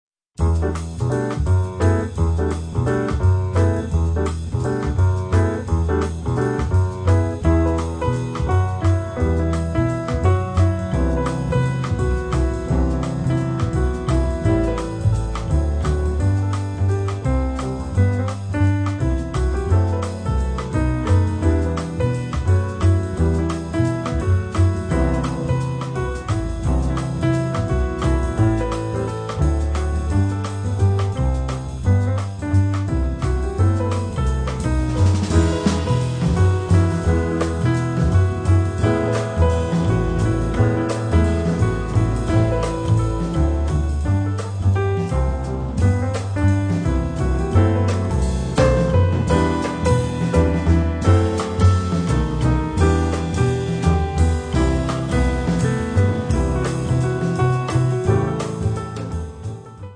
sax contralto
pianoforte
contrabbasso
batteria